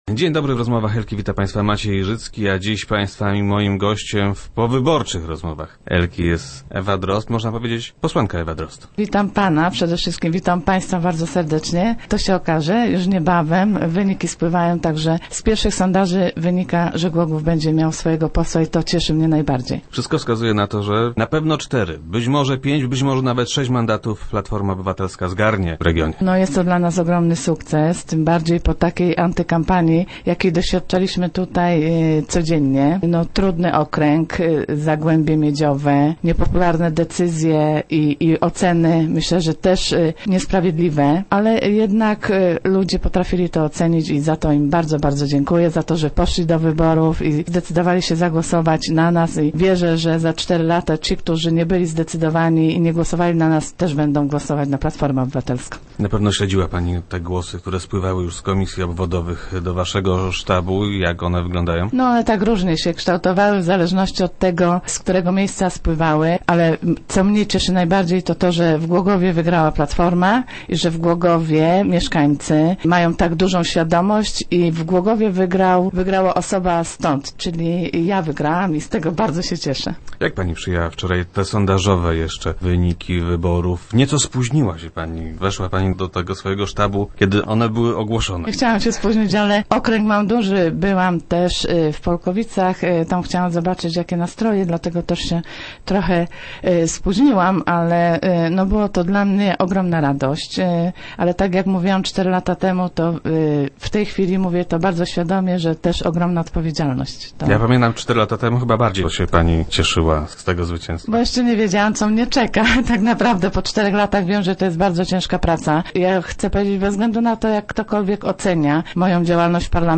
Ewa Drozd była gościem powyborczych Rozmów Elki.